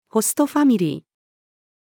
ホストファミリー-female.mp3